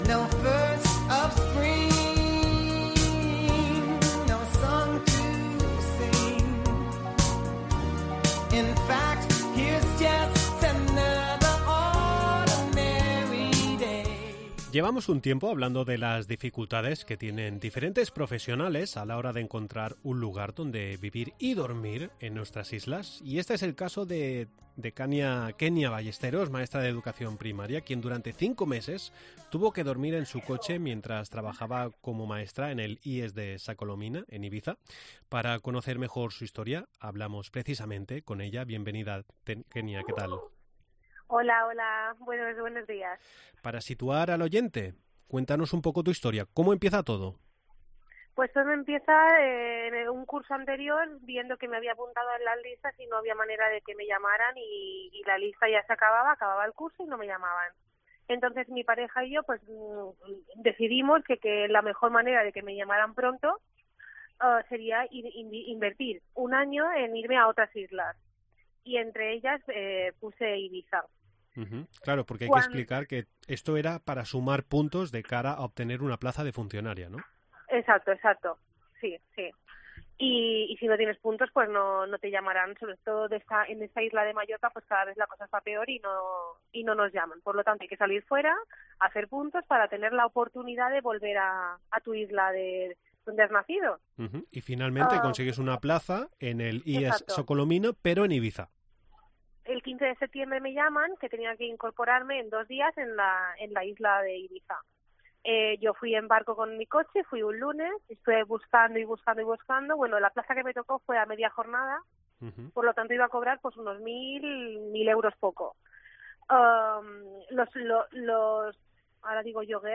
Para conocer mejor la historia hablamos con ella.